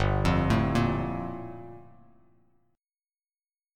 Gm9 Chord
Listen to Gm9 strummed